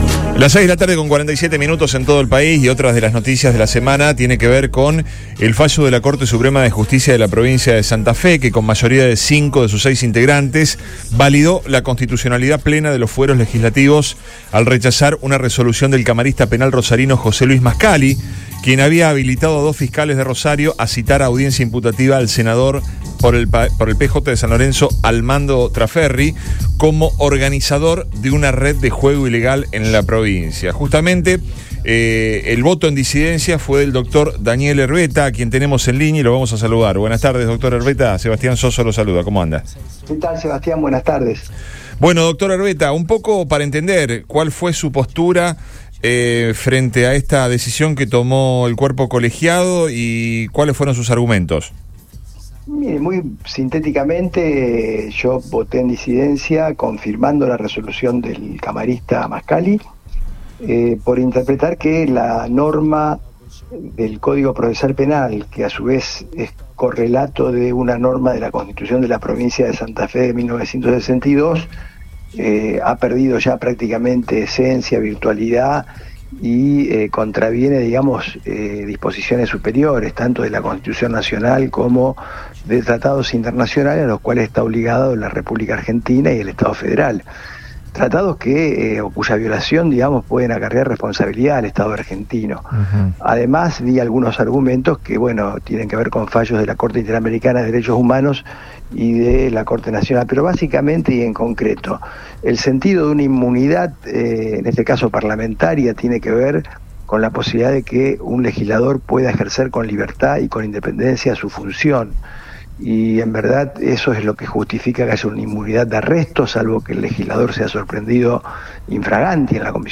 Es por eso que Daniel Erbetta, el único que se opuso a decisión en la votación, dialogó en Después de Todo, por Radio Boing, donde dio su punto de vista.